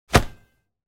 دانلود آهنگ تصادف 44 از افکت صوتی حمل و نقل
دانلود صدای تصادف 44 از ساعد نیوز با لینک مستقیم و کیفیت بالا
جلوه های صوتی